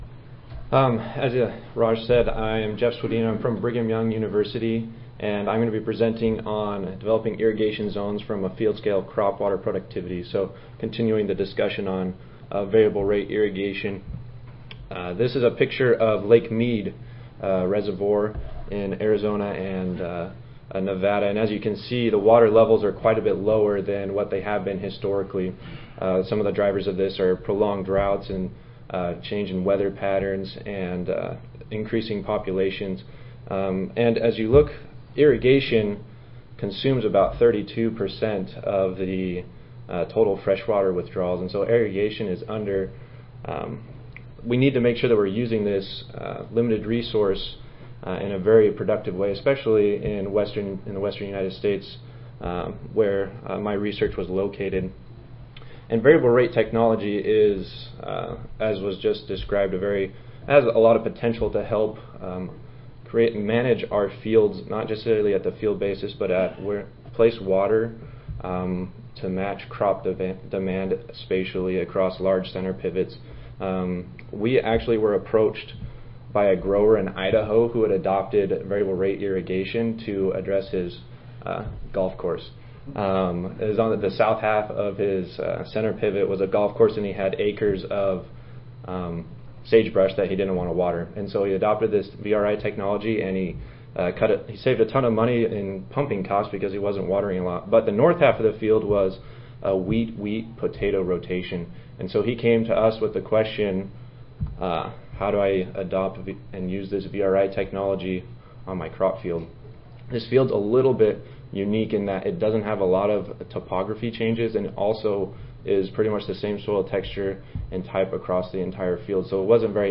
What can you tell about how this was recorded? See more from this Division: ASA Section: Agronomic Production Systems See more from this Session: Development of Tools for Precision Agriculture II